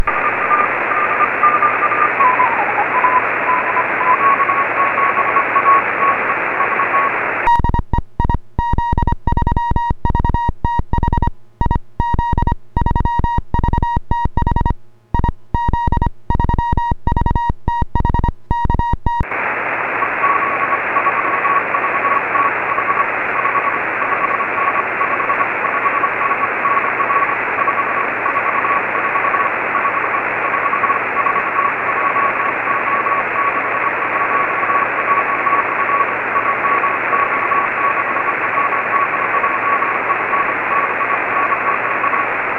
IARU VHF 09/2014 CATEGORIA 6 ORE